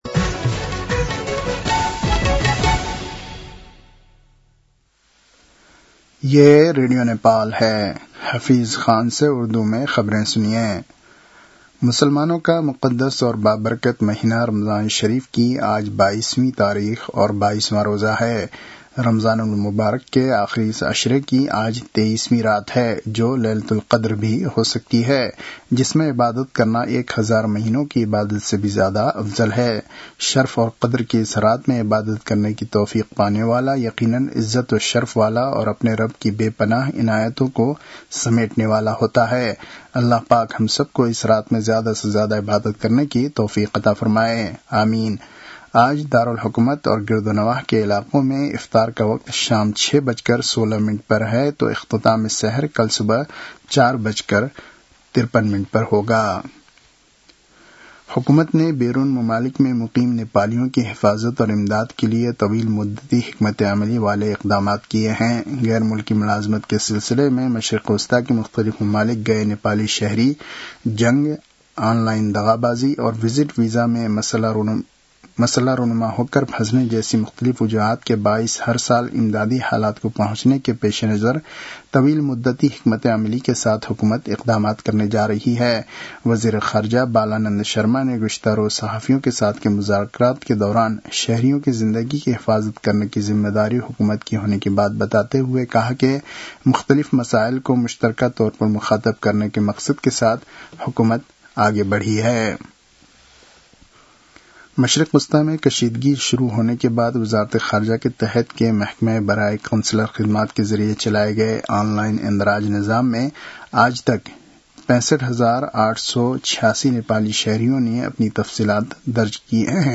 उर्दु भाषामा समाचार : २८ फागुन , २०८२
Urdu-news-11-28.mp3